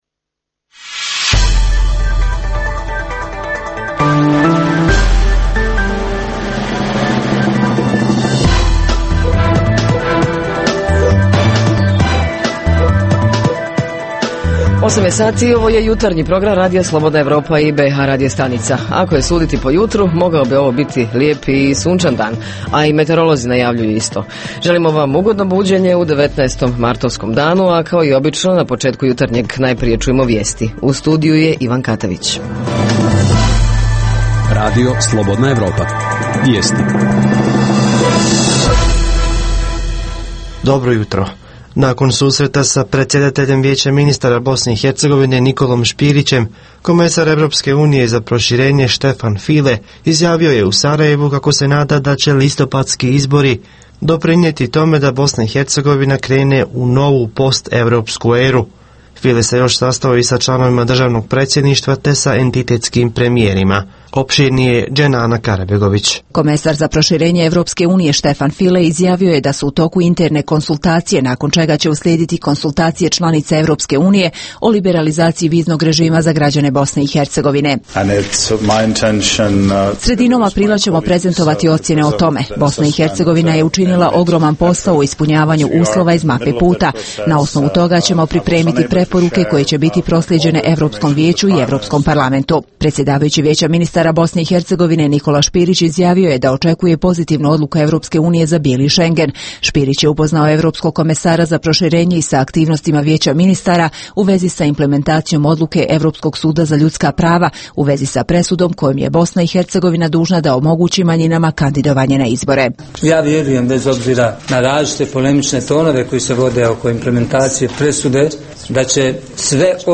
U našoj temi pokušavamo da saznamo neke korisne informacije od stručnog osoblja (psiholozi, psihijatri) o tome kako, na koji način, informisati dijete o opasnosti od pedofila Redovna rubrika Radija 27 petkom je “Za zdrav život". Redovni sadržaji jutarnjeg programa za BiH su i vijesti i muzika.